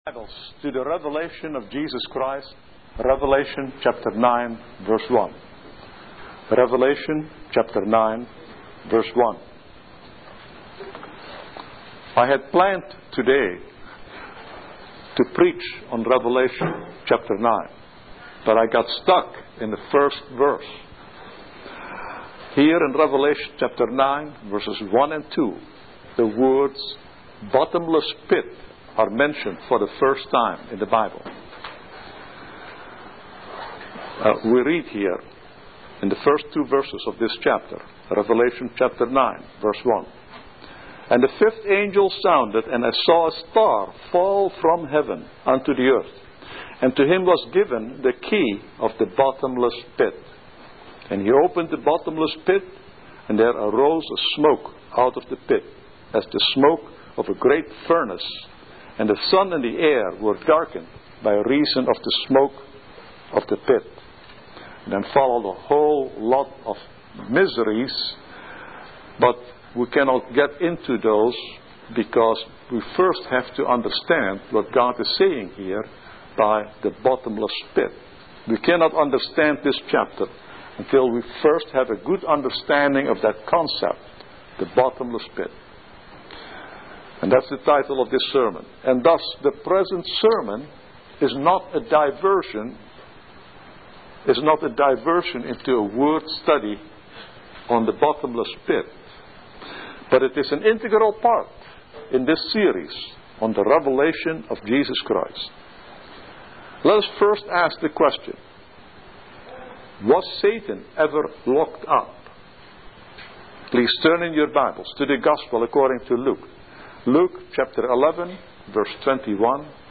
This is an audio sermon